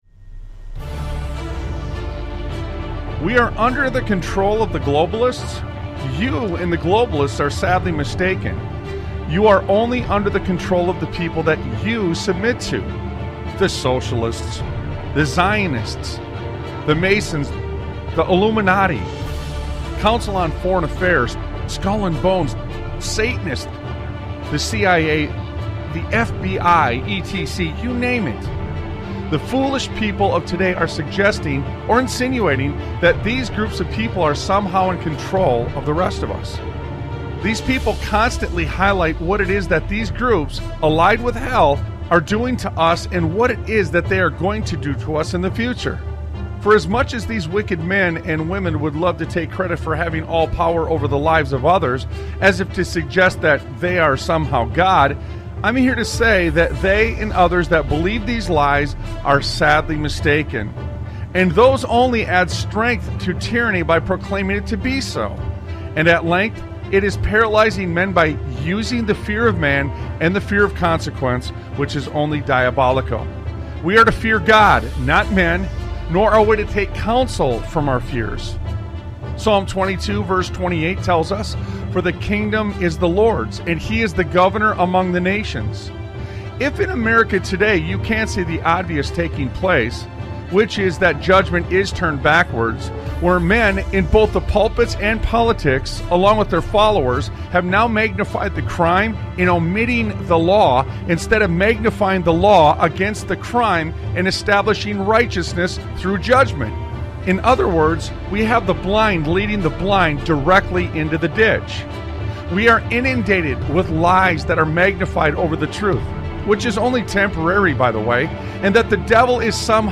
Talk Show Episode, Audio Podcast, Sons of Liberty Radio and Undoing Freedom on , show guests , about Undoing Freedom, categorized as Education,History,Military,News,Politics & Government,Religion,Christianity,Society and Culture,Theory & Conspiracy